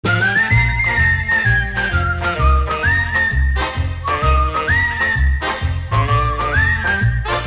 • Качество: 128, Stereo
позитивные
без слов
Позитивная короткая мелодия на сообщение